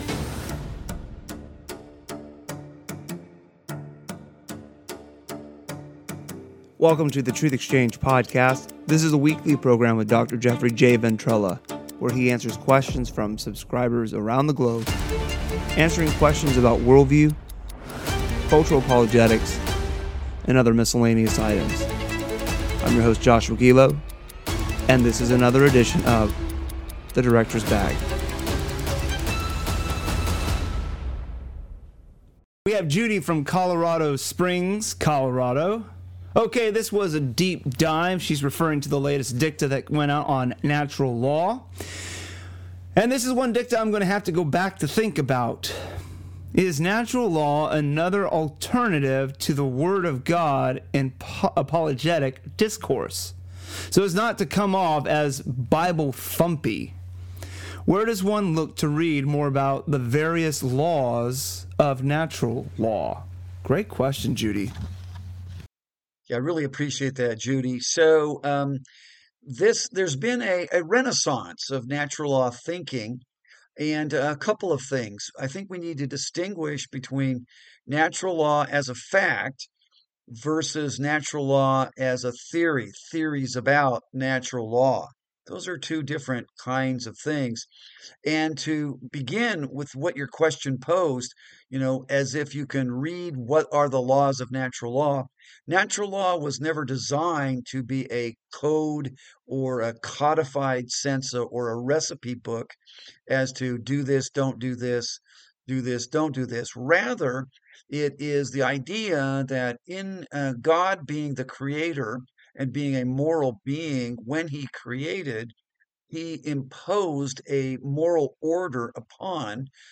Welcome to the Truth Exchange podcast.